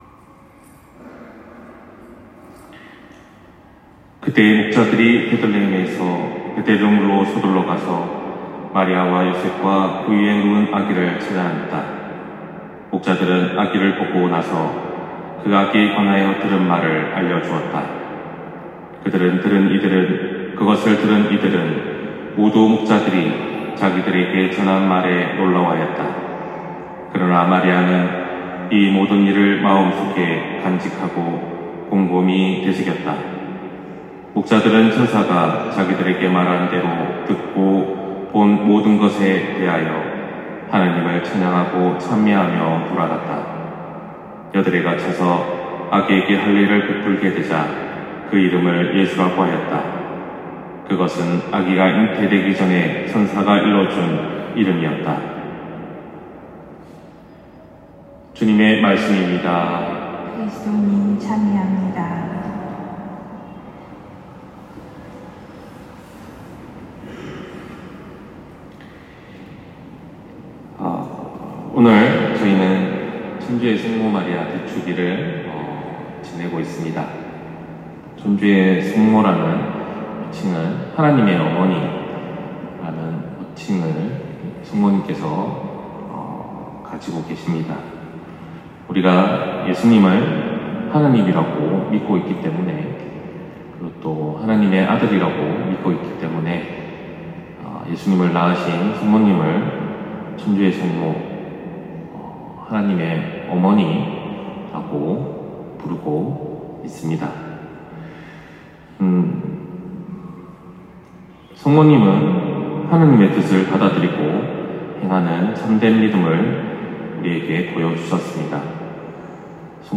신부님강론말씀